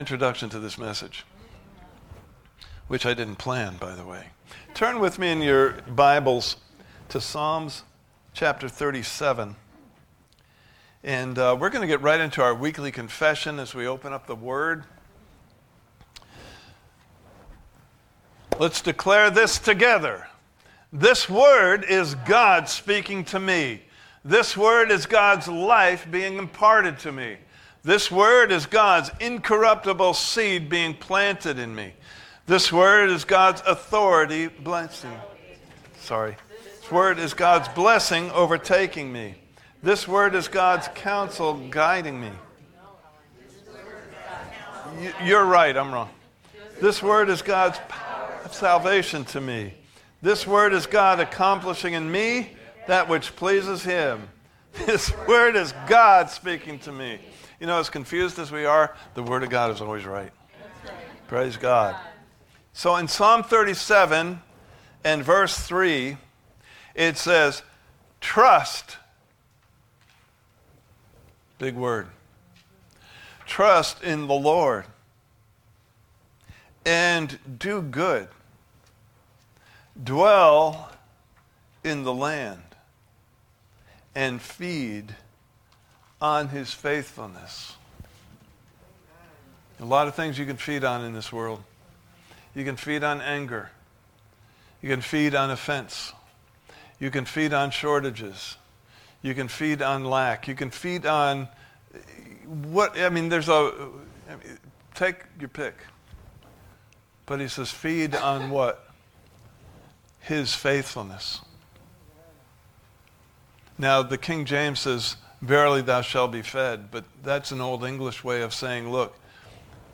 Service Type: Sunday Morning Service « Part 1: Feeding on God’s Faithfulness (Video) Part 2: Worry Drives – Rest Receives and Revives!